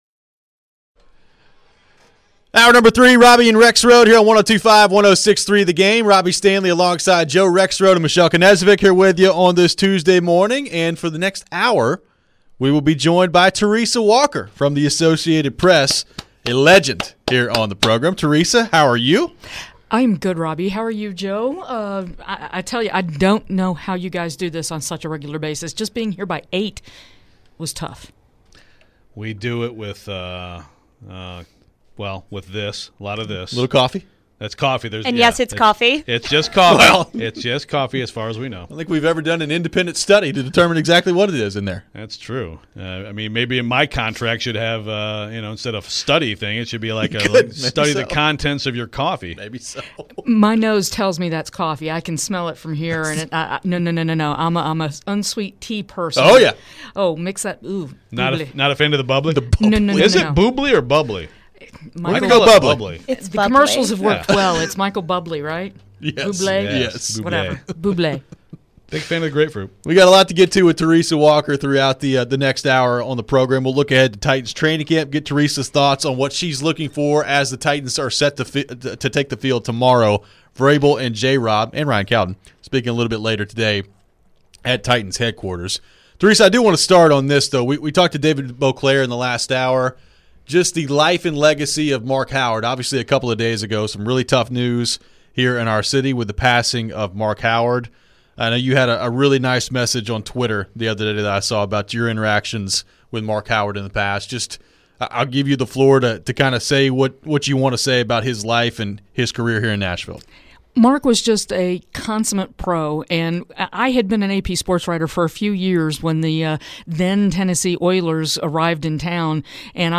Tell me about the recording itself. for the entire hour in studio